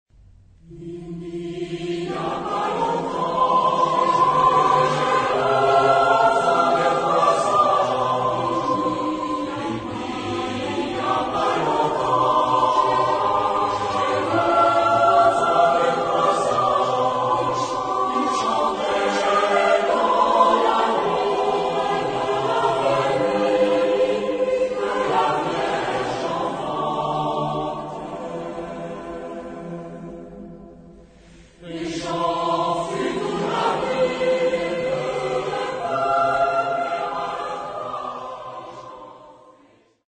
Genre-Style-Form: Secular ; Christmas song
Type of Choir: SATB  (4 mixed voices )
Tonality: E minor